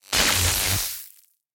electricity.ogg